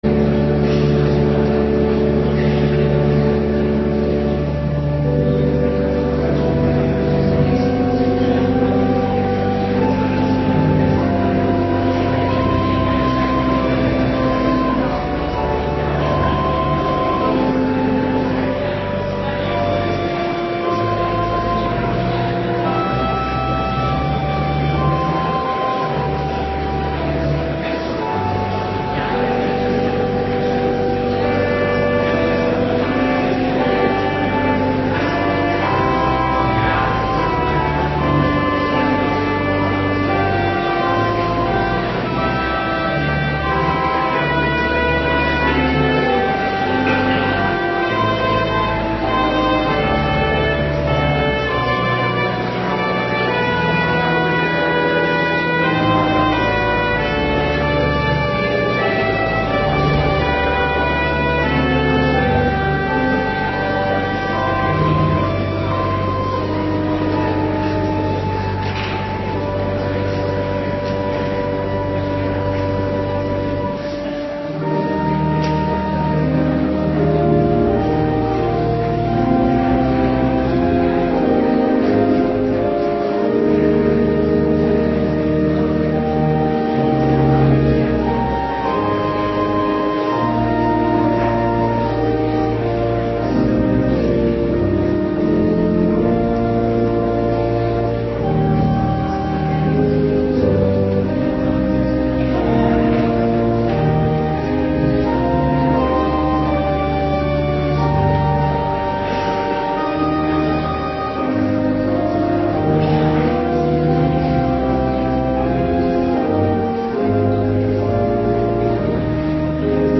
Morgendienst 22 maart 2026